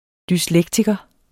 Udtale [ dysˈlεgtigʌ ]